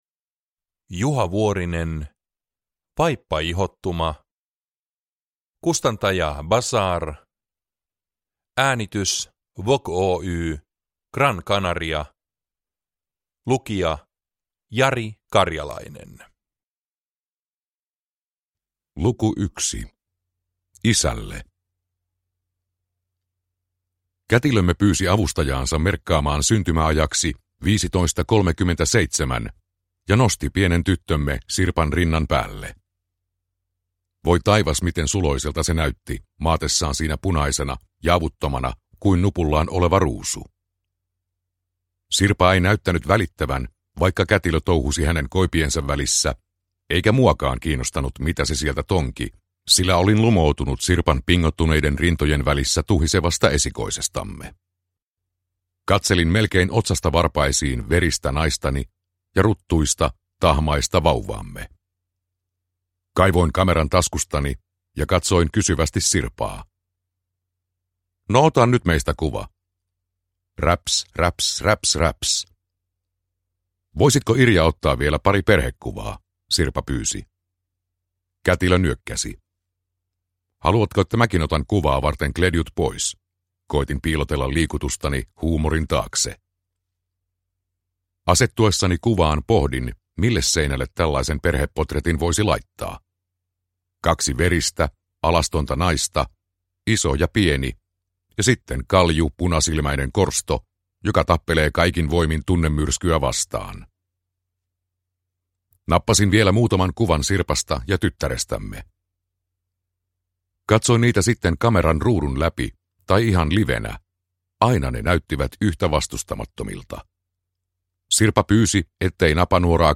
Vaippaihottuma – Ljudbok